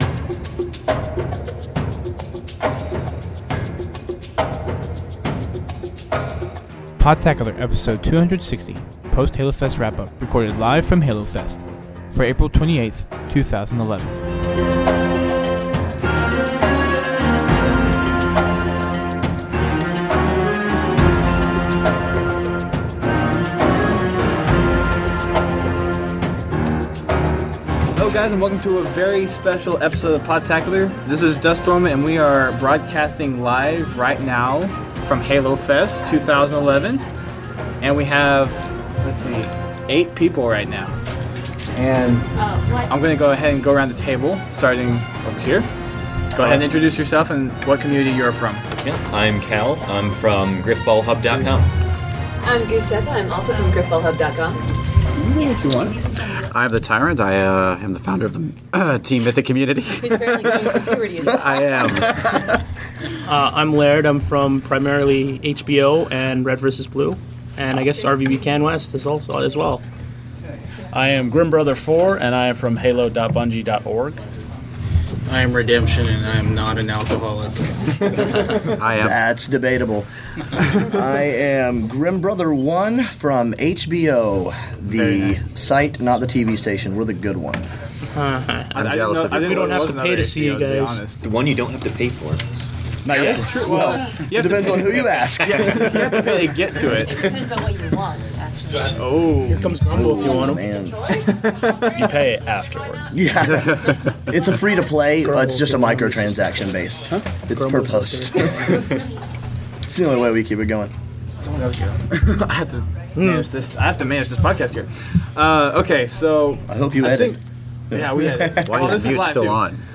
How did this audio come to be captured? Recorded live from Halo Fest